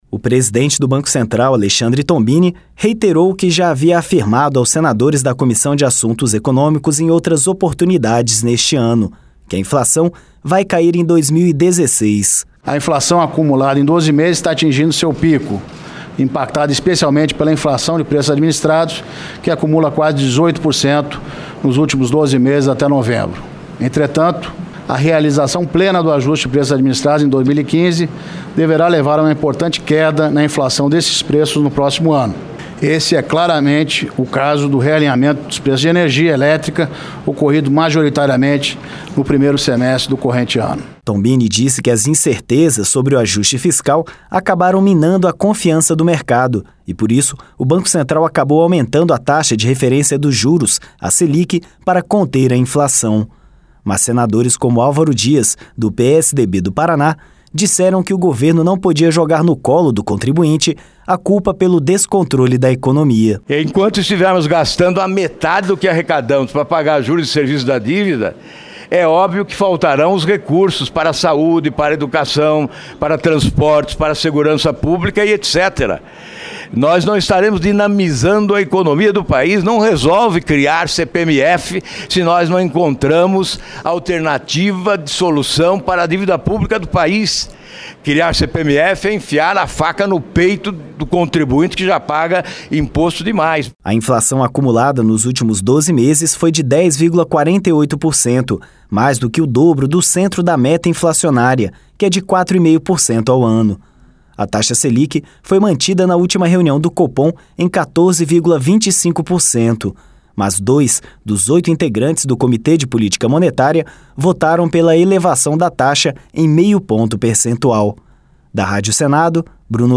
A Comissão de Assuntos Econômicos do Senado (CAE) recebeu nesta terça-feira (15), para uma audiência pública, o presidente do Banco Central, Alexandre Bombini para discutir as diretrizes e perspectivas da política monetária. O presidente do BC afirmou que espera a queda na inflação em 2016, mas ouviu de senadores críticas sobre o aumento na taxa de juros.